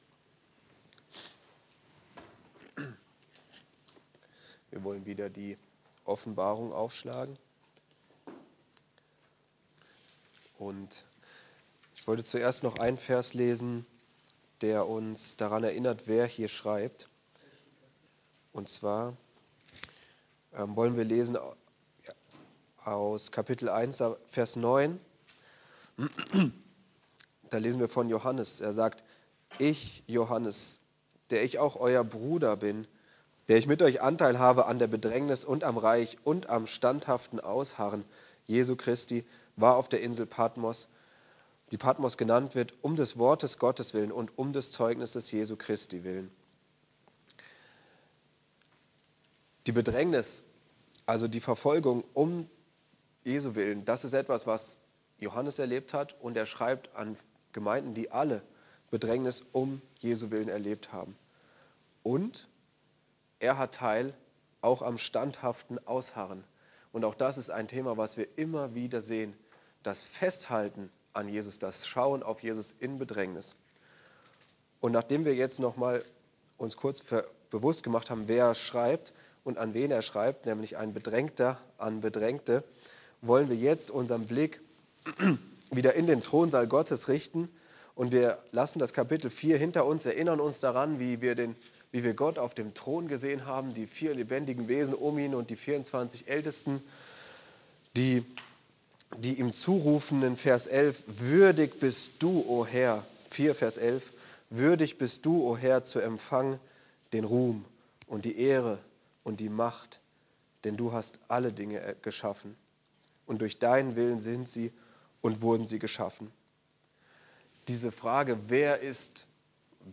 Würdig ist das Lamm! (Andacht Gebetsstunde)